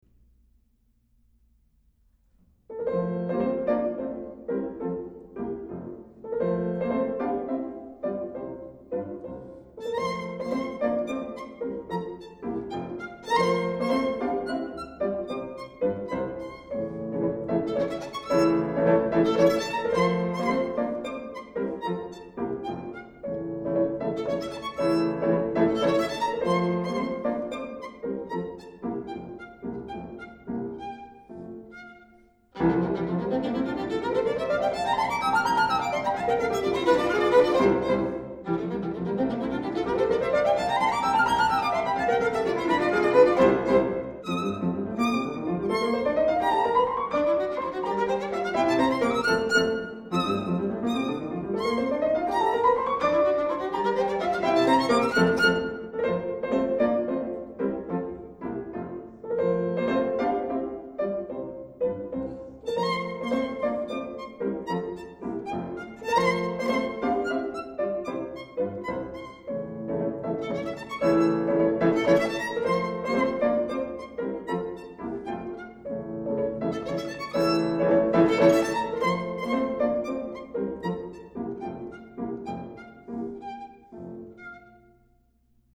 piano
violin